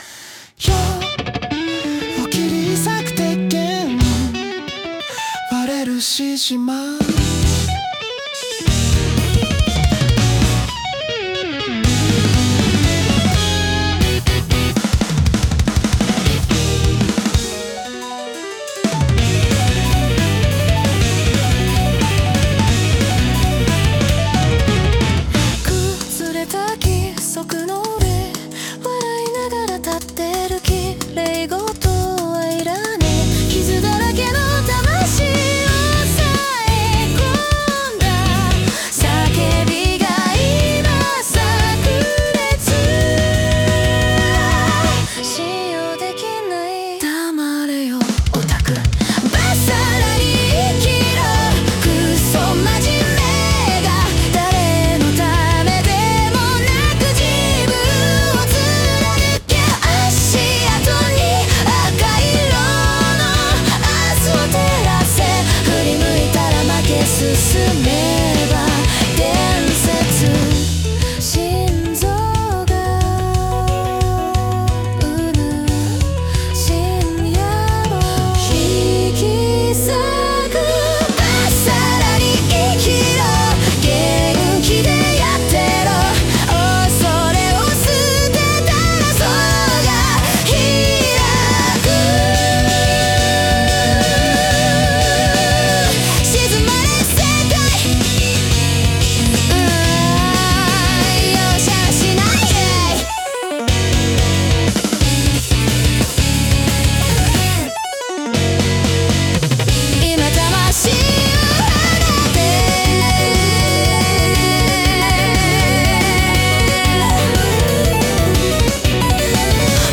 女性ボーカル
イメージ：オルタナティブ・ロック,邦ROCK,女性ボーカル,かっこいい